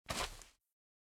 sounds / mob / turtle / walk3.ogg